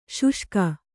♪ śuṣka